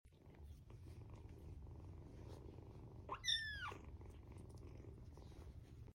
Tuxedo kitty sounds: purr and sound effects free download
Tuxedo kitty sounds: purr and meowing kitten to give you a smile